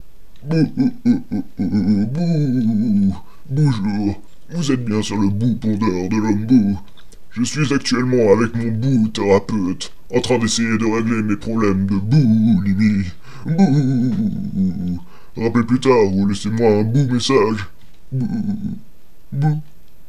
Les Répondeurs téléphoniques
Les répondeurs téléphoniques de vos Bat'héros préférés en mp3!!!!!